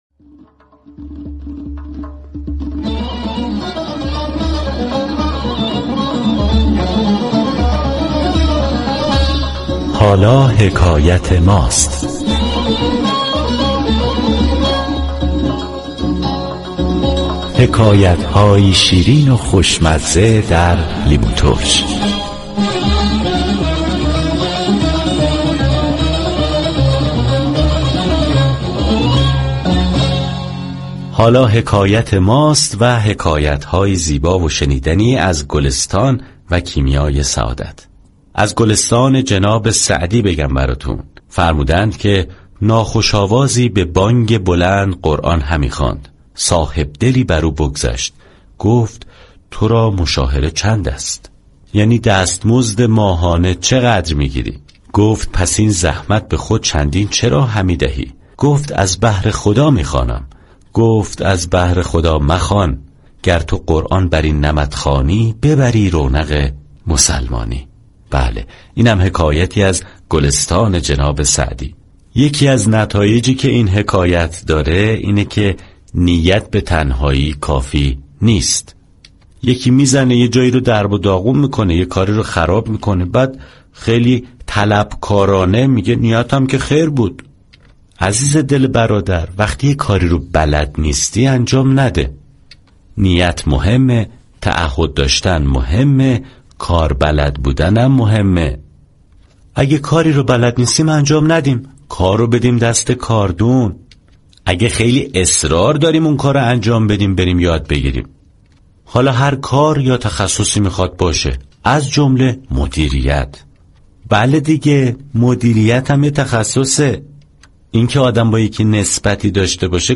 حكایت طنز